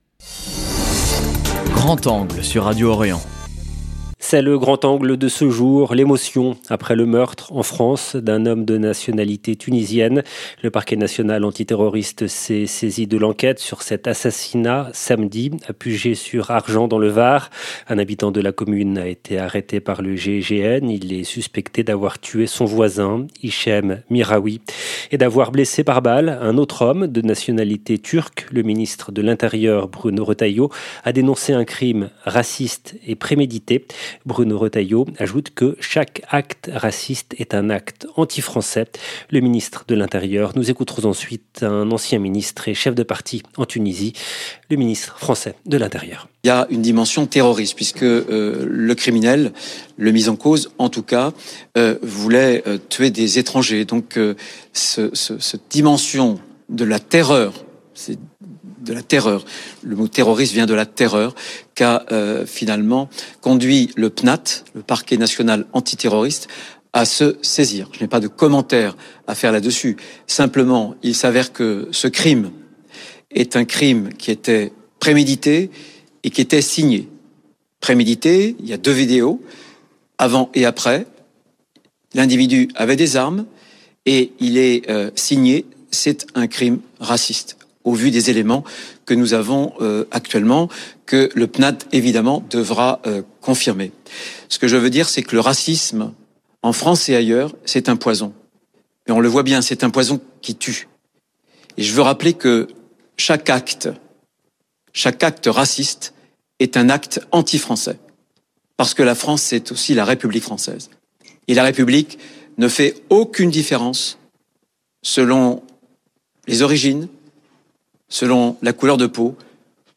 L’Invité du Grand Angle pour en parler est Khalil Zaouia , ancien ministre tunisien et chef de parti. 0:00 8 min 16 sec